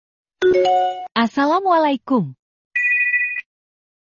Nada notifikasi Assalamualaikum
Kategori: Nada dering
Suara islami yang unik ini menambah kesan santun pada pesan masuk Anda.
nada-notifikasi-assalamualaikum-id-www_tiengdong_com.mp3